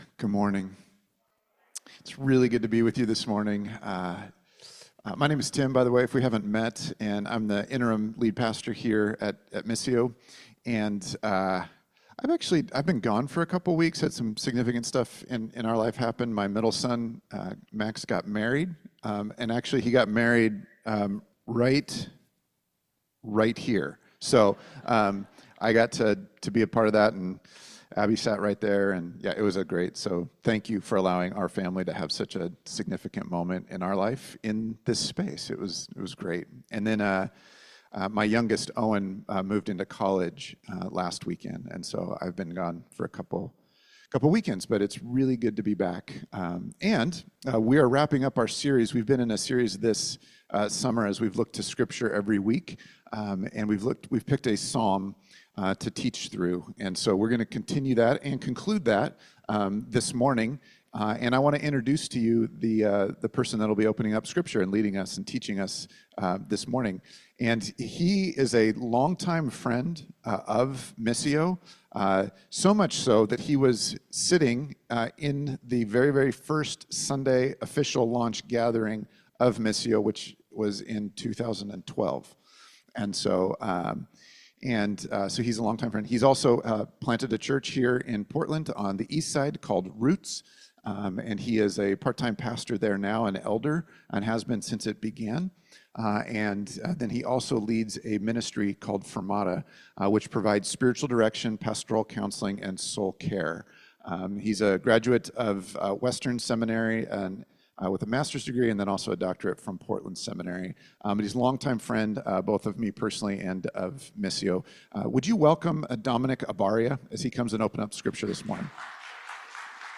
Soundtrack for a Flourishing Life Current Sermon
Guest Speaker